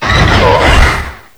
cry_not_melmetal.aif